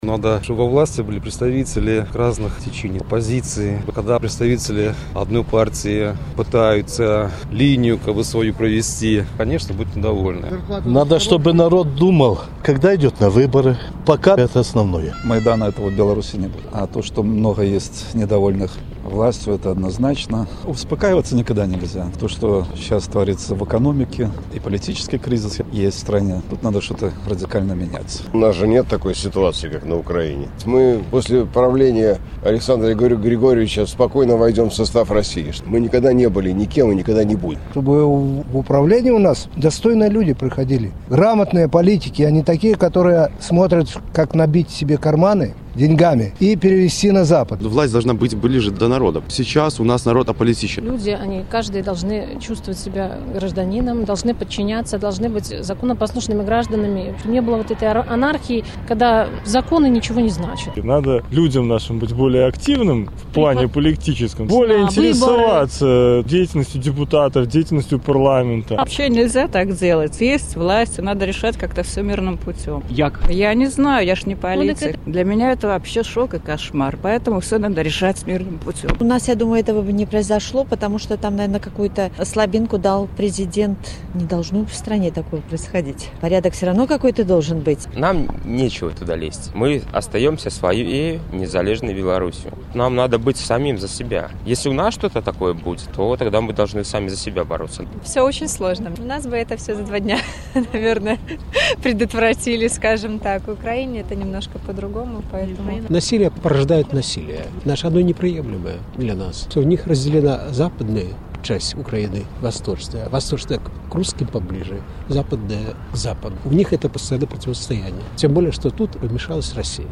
Адказваюць магілёўцы